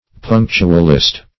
Search Result for " punctualist" : The Collaborative International Dictionary of English v.0.48: Punctualist \Punc"tu*al*ist\, n. One who is very exact in observing forms and ceremonies.